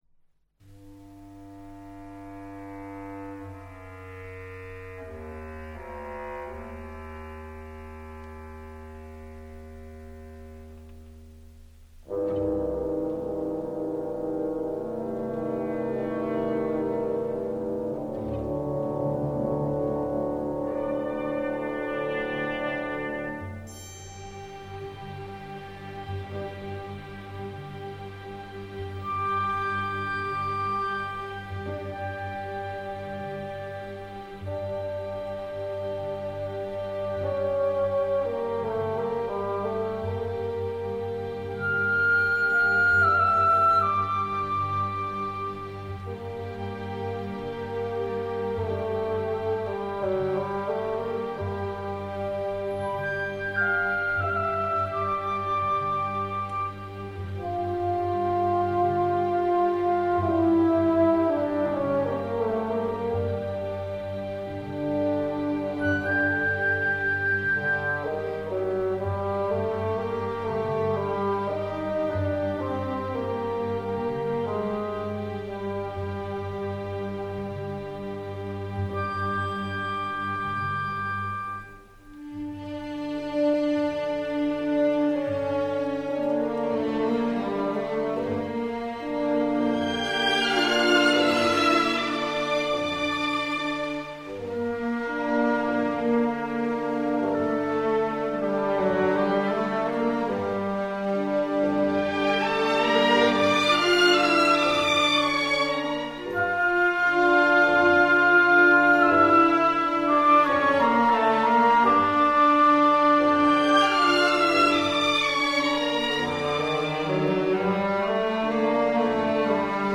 ciprian-porumbescu-rapsodia-romana-pentru-orchestra.mp3